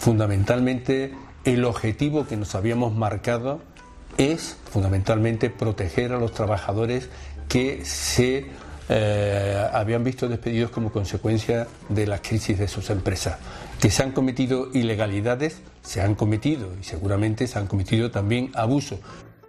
Declaraciones de Manuel Chaves